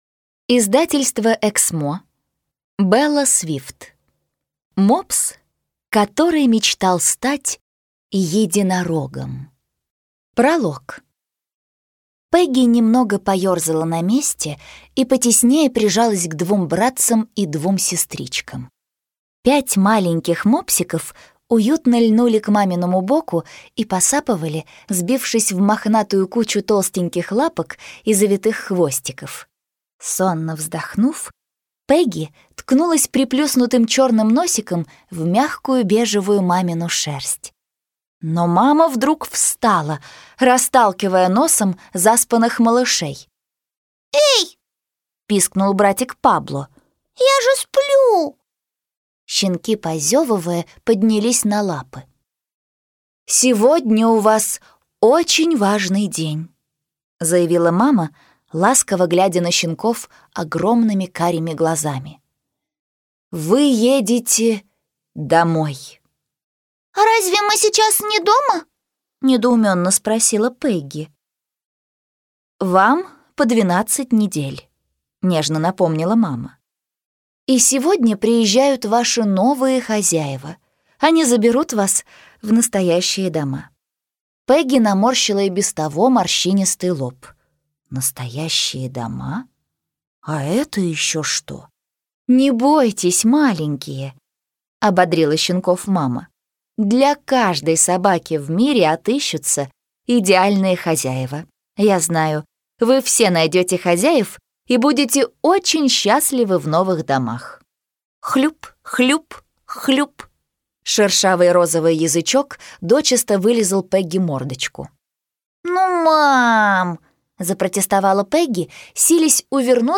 Аудиокнига Мопс, который мечтал стать единорогом | Библиотека аудиокниг